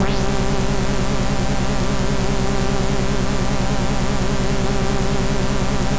Index of /90_sSampleCDs/Trance_Explosion_Vol1/Instrument Multi-samples/Scary Synth
G1_scary_synth.wav